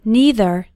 Both either and neither have two possible pronunciations:
Pronunciation #2 – NEITHER